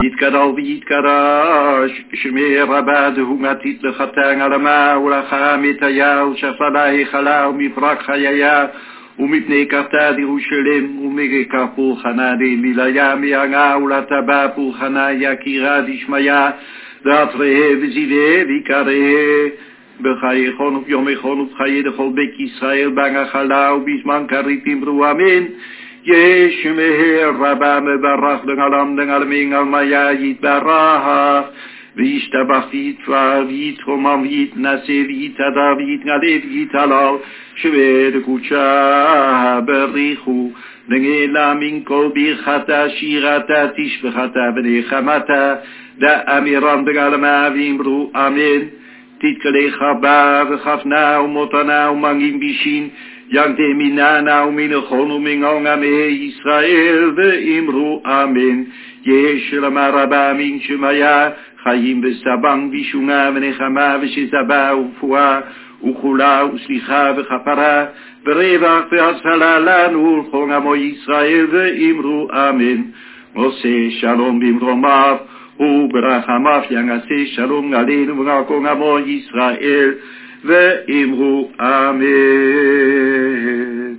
Mitsva (funeral)
Avelim